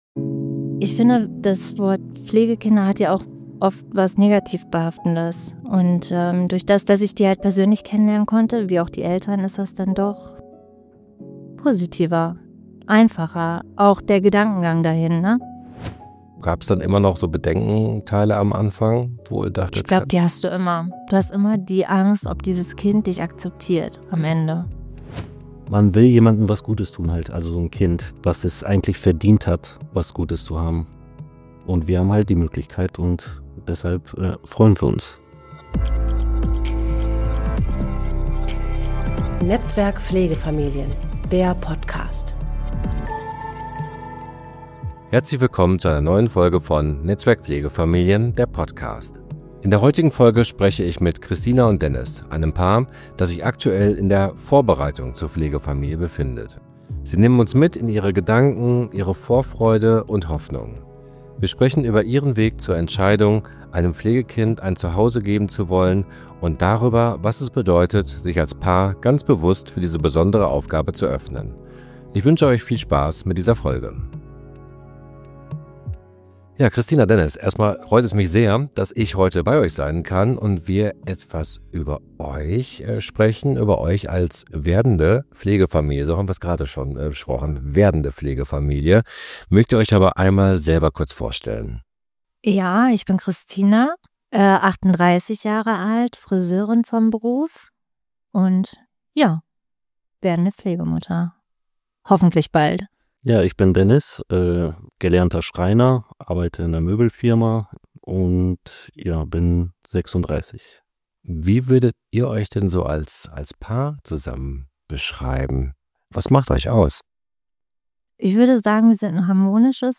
Ein ehrliches Gespräch über Fragen, Vorfreude, Zweifel – und den Mut, neue Wege zu gehen.